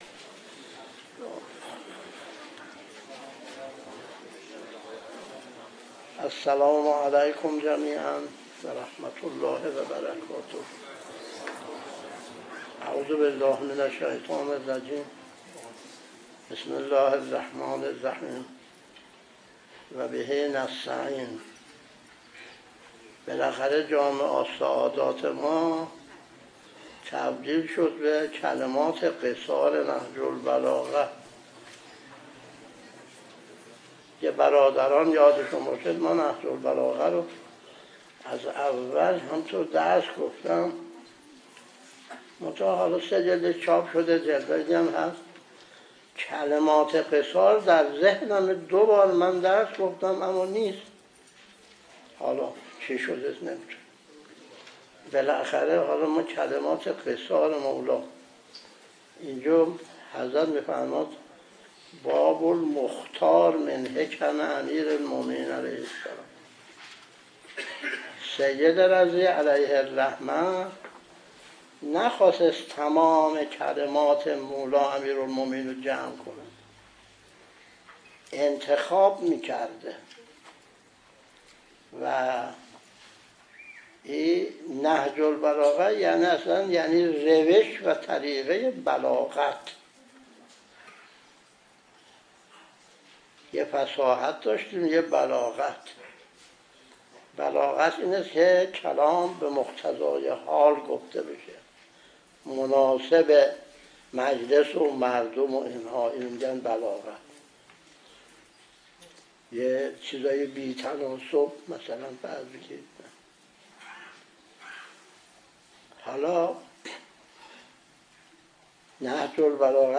درس 1 : (25/8/1388)